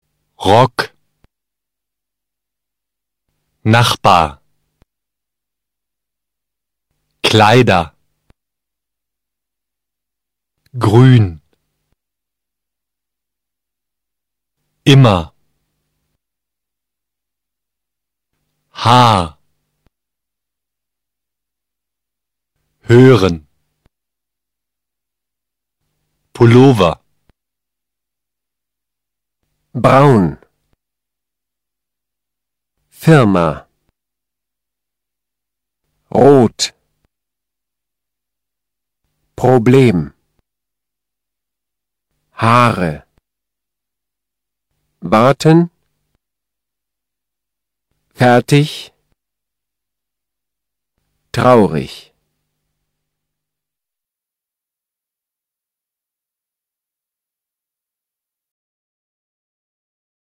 Here you will find practical exercises which will help you learn how to pronounce typical German sounds.
RRRRRRR